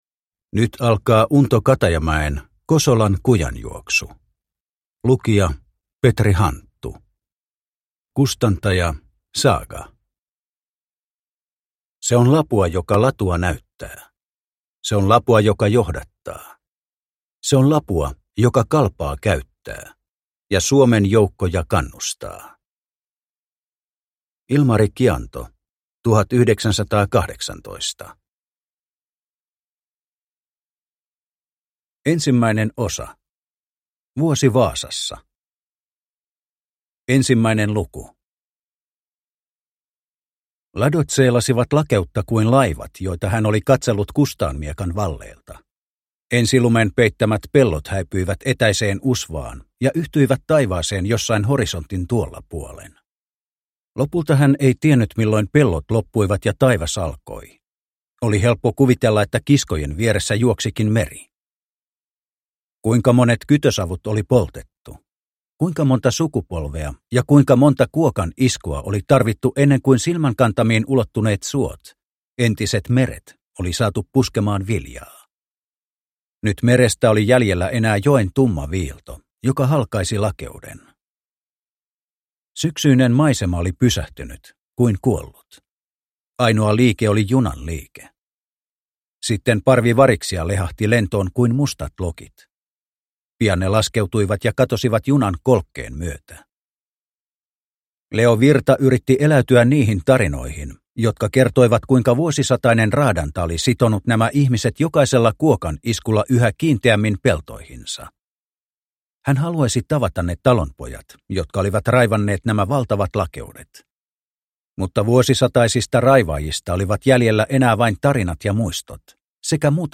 Kosolan kujanjuoksu – Ljudbok – Laddas ner